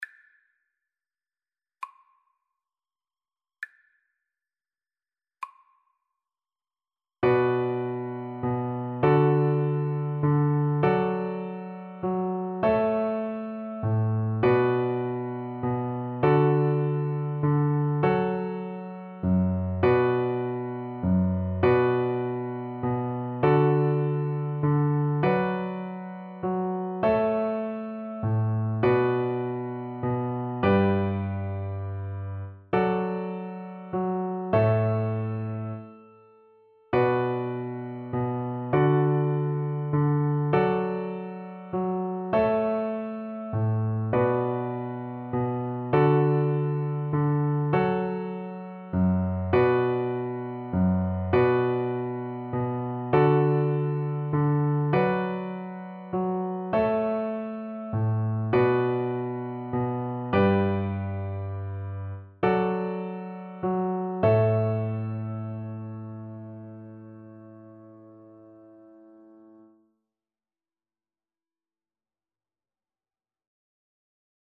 Play (or use space bar on your keyboard) Pause Music Playalong - Piano Accompaniment Playalong Band Accompaniment not yet available reset tempo print settings full screen
D major (Sounding Pitch) (View more D major Music for Cello )
6/8 (View more 6/8 Music)